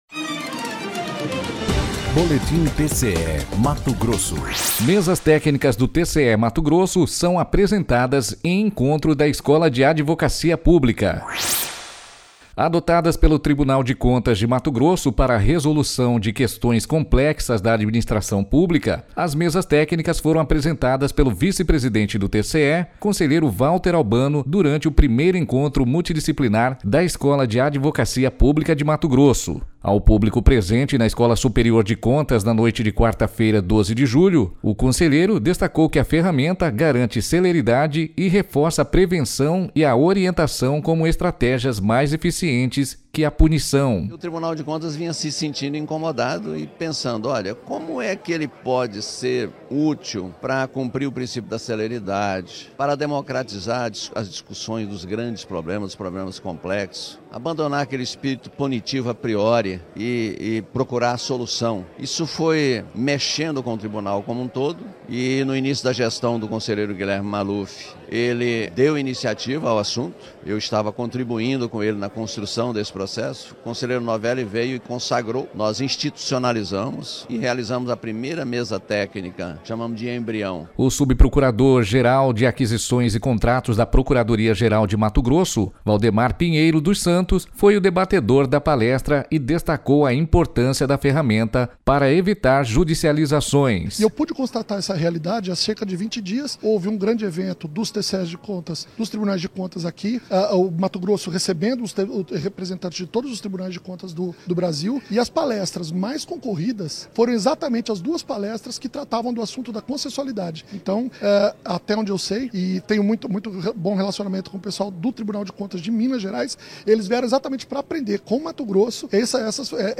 Sonora: Valter Albano - conselheiro vice-presidente do TCE-MT
Sonora: Emerson Cajango - juiz auxiliar da CGJ-MT